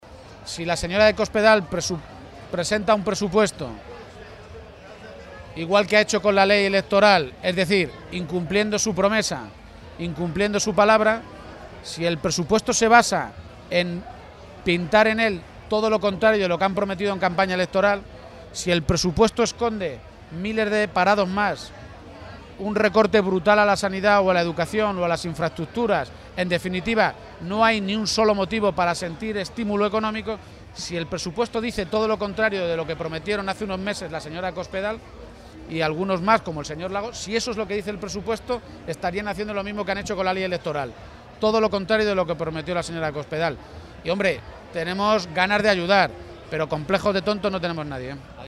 Emiliano García-Page, Secretario General del PSCM-PSOE
Respondía así García-Page a preguntas de los medios de comunicación, durante su visita a la Feria de Talavera de la Reina, sobre la posibilidad de acordar las cuentas regionales de este año, que aún no están presentadas a pesar de estar ya a mediados del mes de mayo.